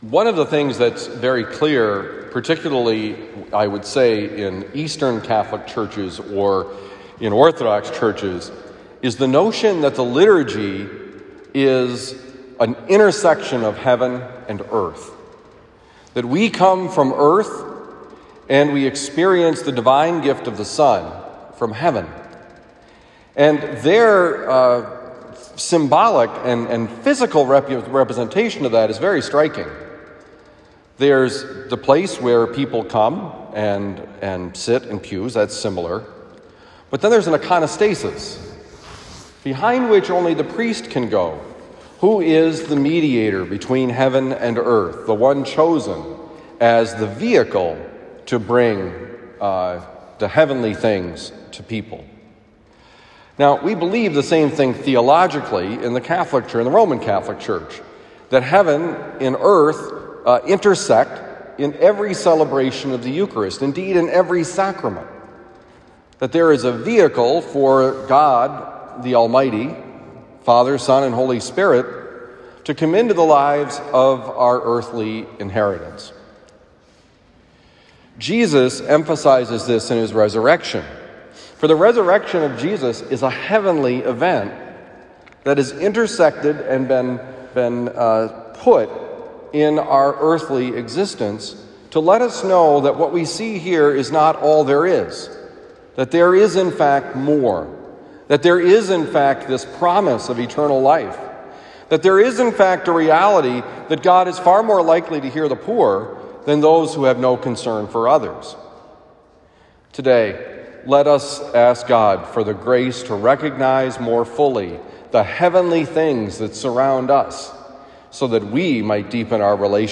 Homily for Thursday, April 15, 2021
Given at Christian Brothers College High School, Town and Country, Missouri.